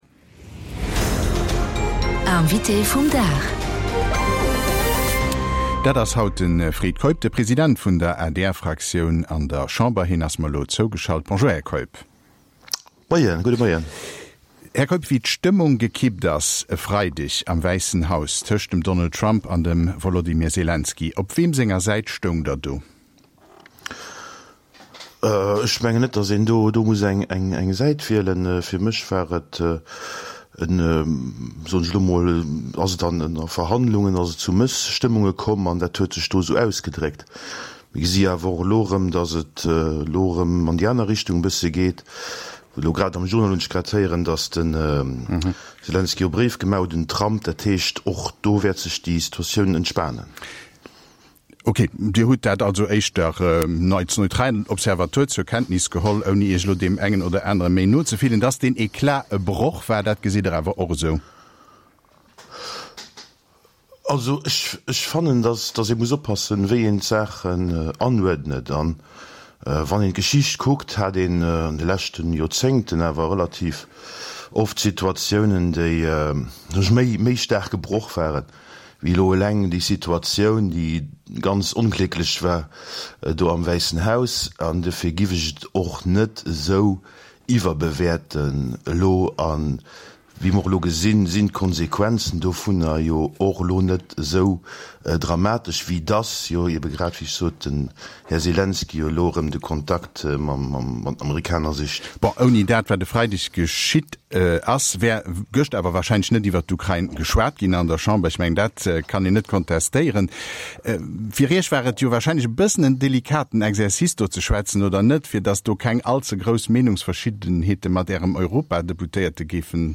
Invitéen am Mëttelpunkt vun der Aktualitéit: Mir stellen hinnen déi Froen, déi Dir Iech och stellt.